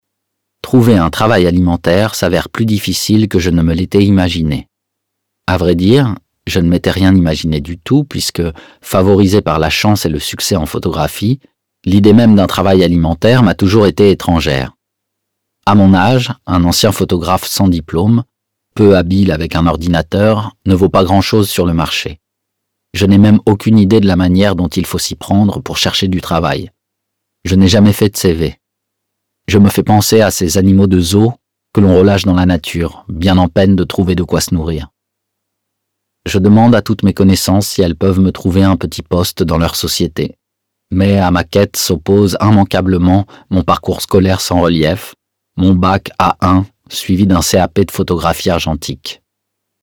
« A pied d’oeuvre » de Franck Courtès, lu par Bastien Bouillon